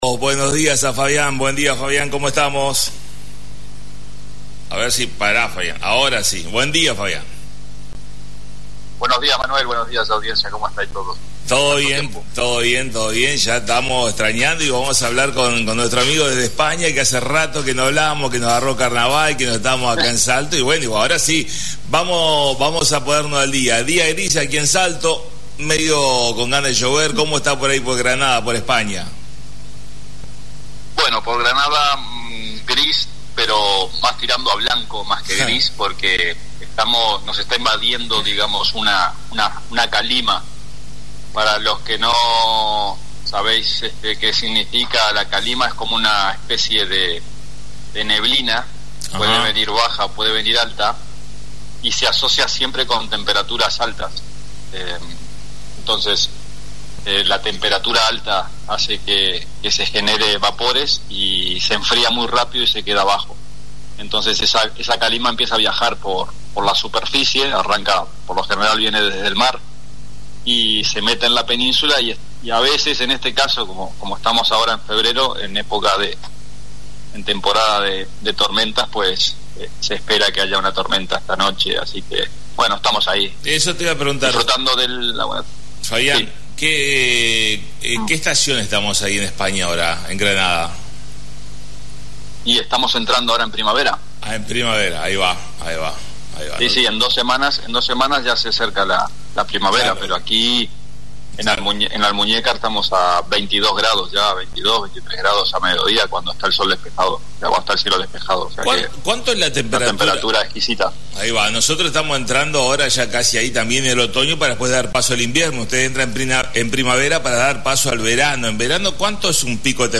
Comunicación en vivo desde Granada España